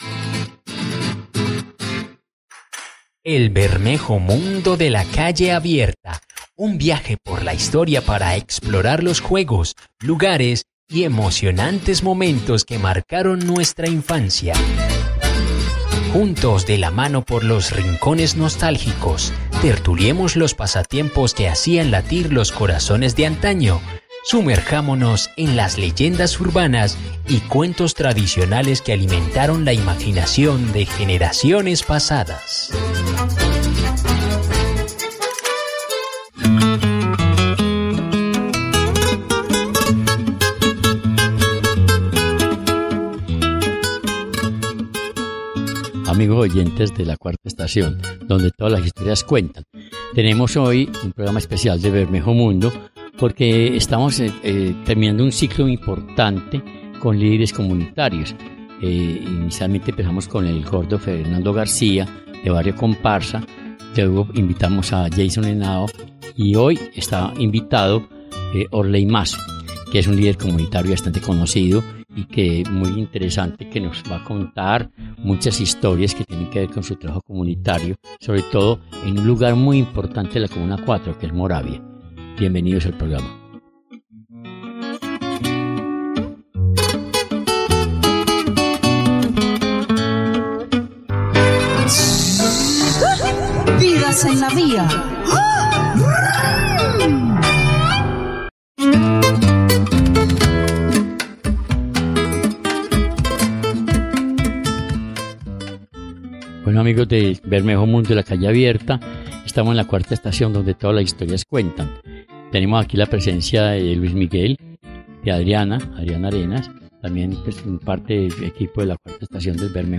nuestros locutores